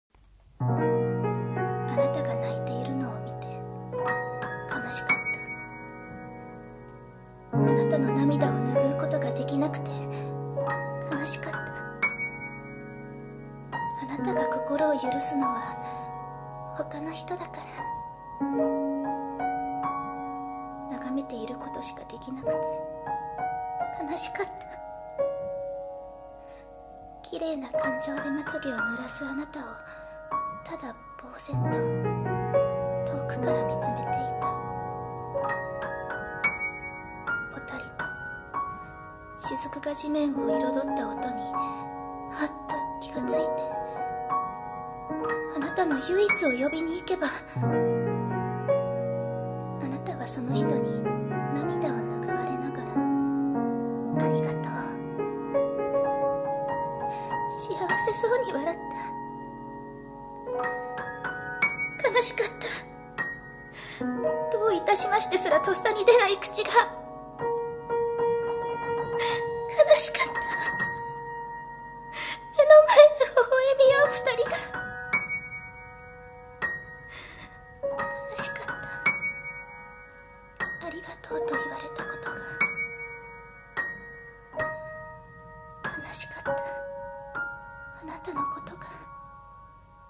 【声劇】かなしいひと